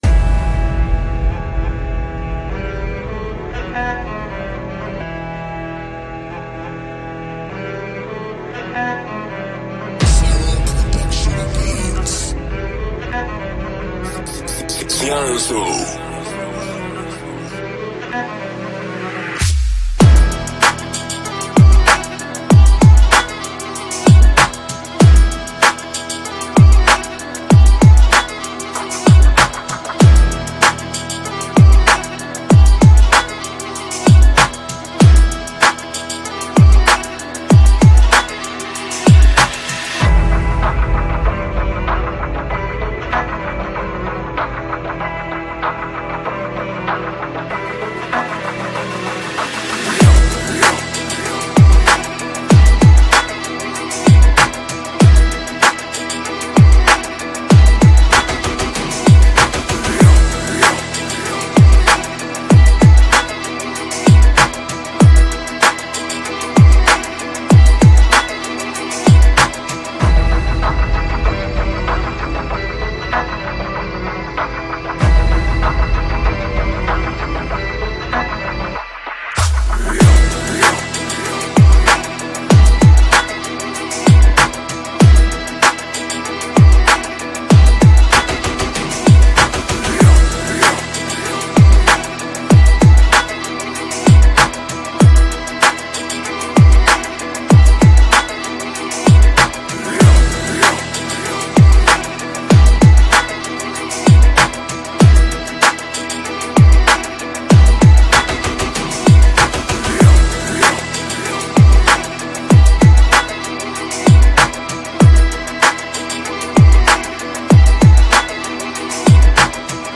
سیستمی خفن فاز بالا بیس دار ویبره سنگین